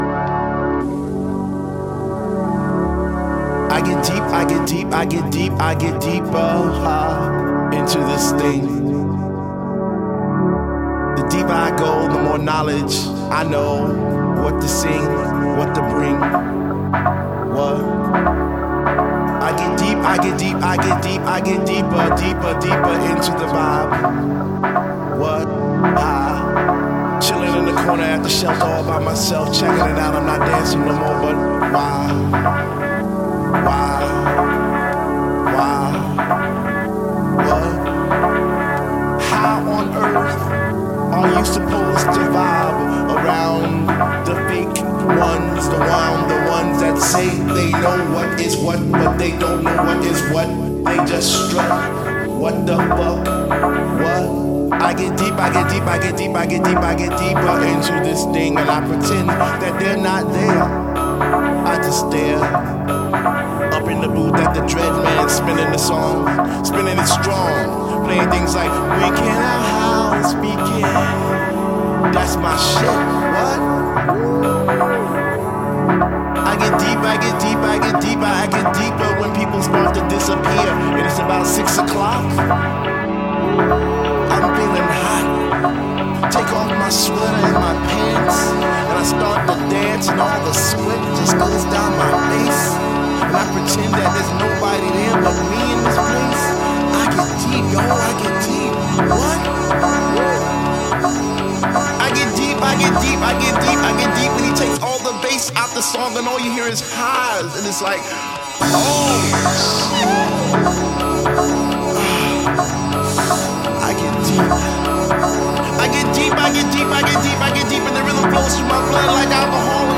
Styl: House, Techno, Breaks/Breakbeat